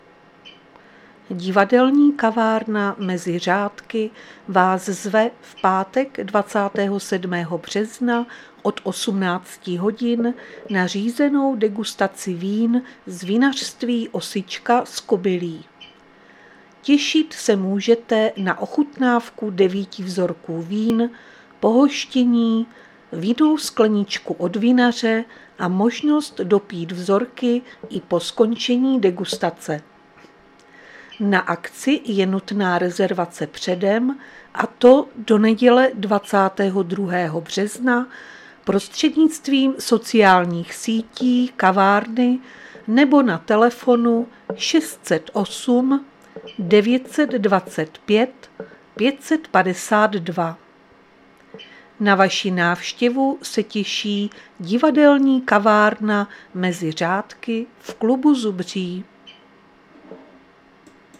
Záznam hlášení místního rozhlasu 19.3.2026
Zařazení: Rozhlas